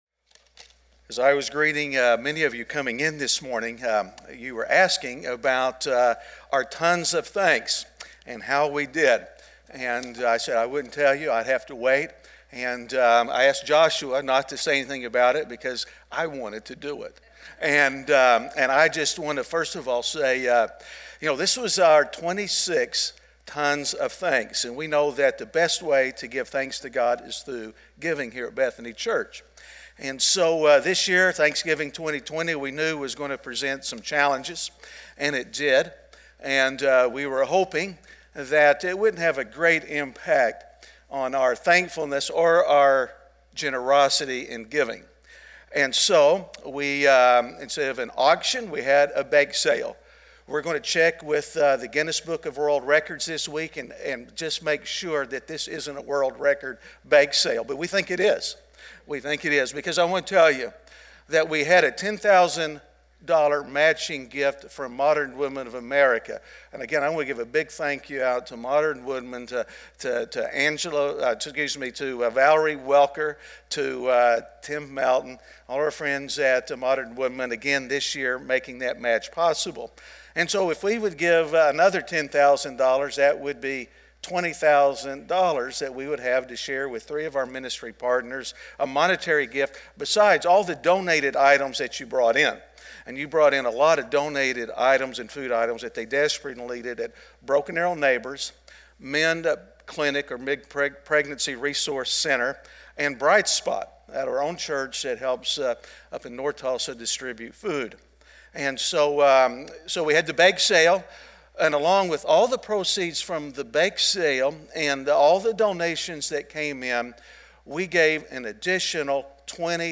Advent - He Keeps His Promises (Week 1) - Sermon.mp3